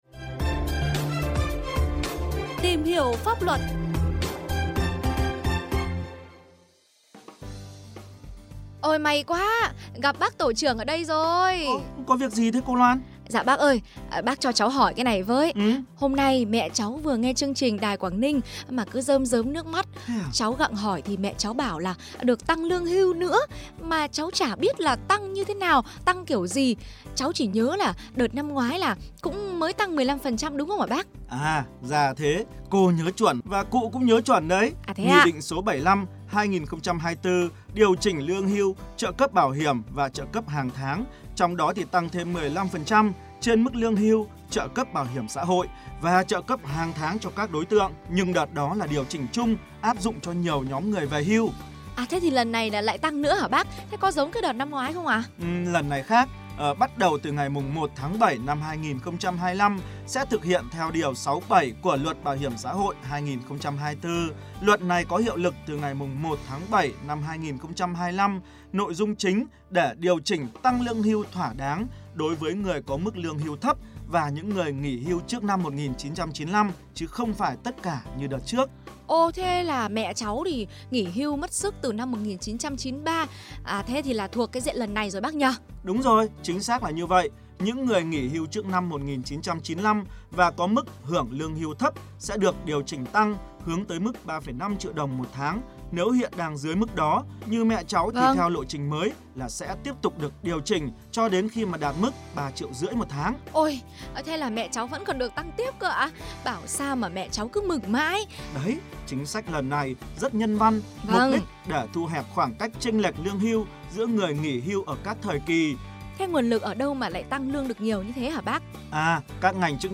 (Phóng sự phát thanh) Tăng lương hưu lần 3 từ 1/7/2025 cho người nghỉ hưu trước 1995